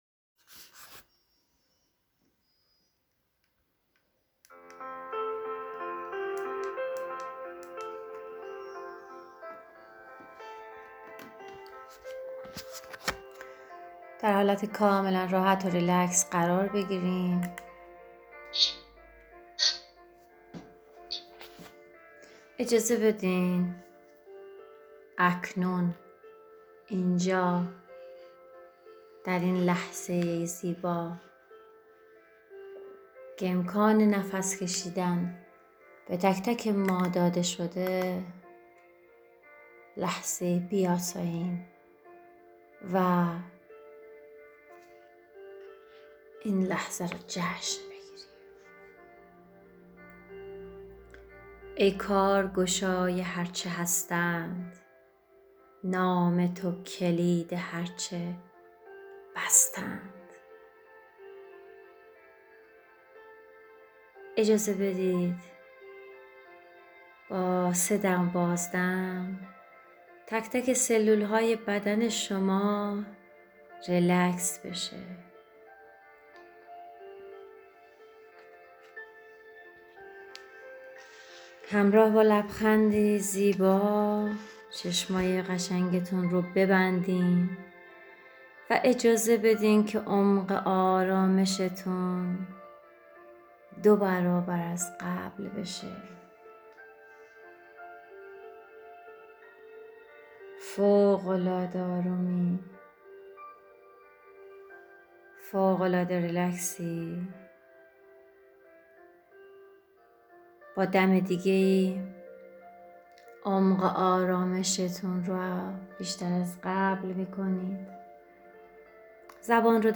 مراقبه تشکر از خداوند